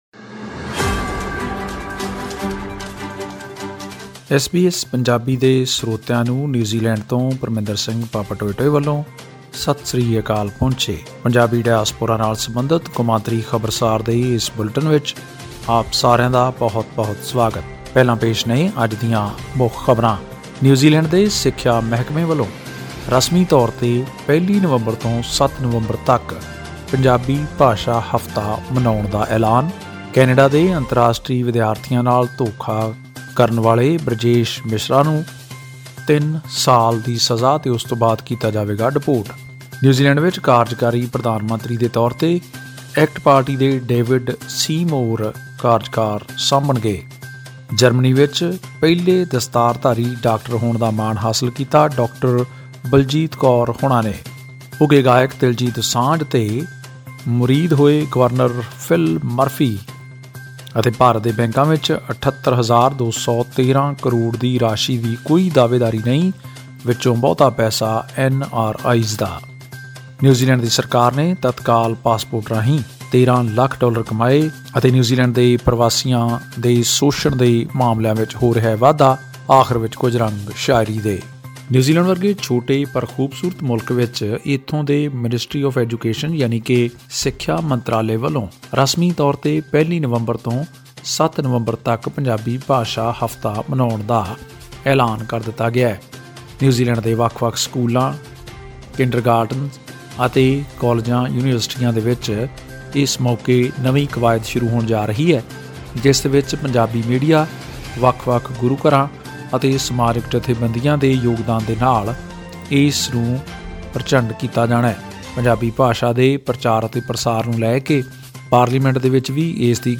ਖਾਸ ਰਿਪੋਰਟ